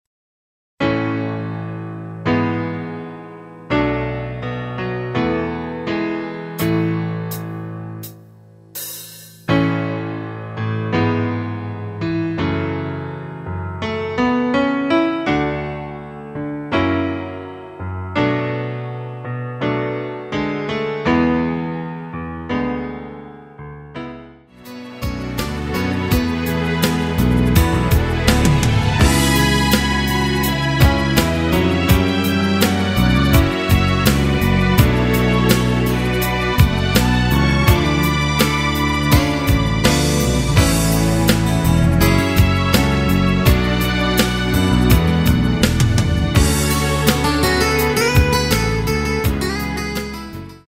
전주없이 노래 들어가는 곡이라 전주 만들어 놓았습니다.